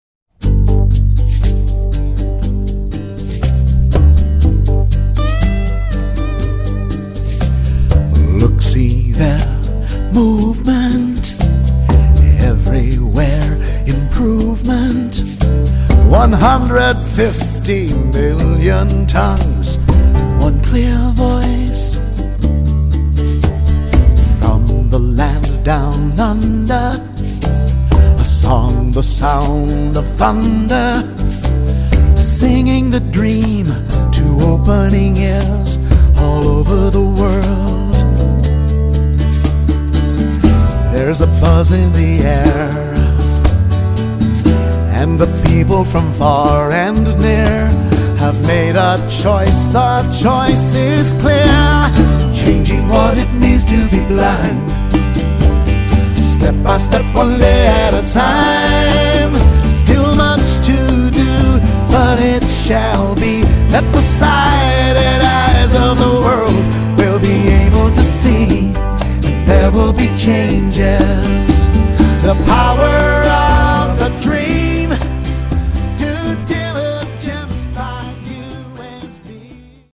lead vocal
electric guitar & harmony vocals
acoustic guitars, djembe, ukulele
electric piano
djembe, clave, shakers, conga, guiro, mouth
electric bass